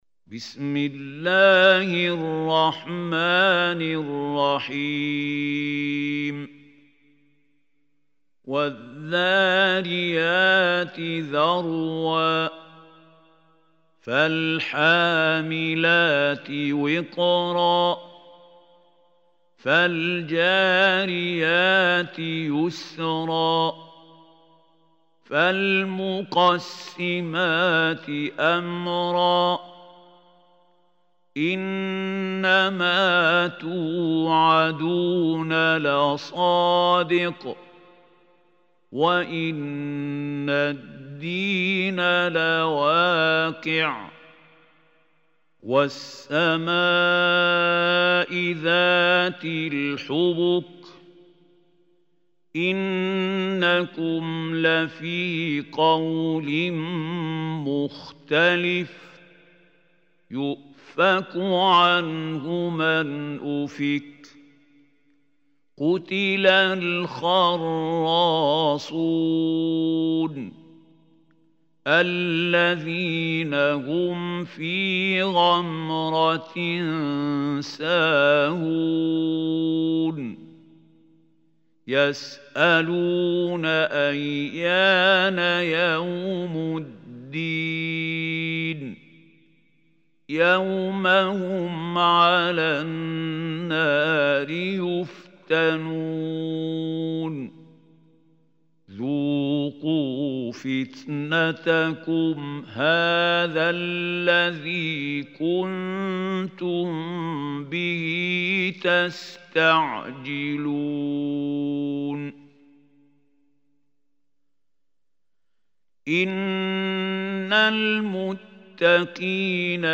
Surah Adh-Dhariyat Recitation by Khalil Hussary
Surah Adh-Dhariyat is 51 surah of Holy Quran. Listen or play online mp3 tilawat / recitation in the beautiful voice of Sheikh Mahmoud Khalil Al Hussary.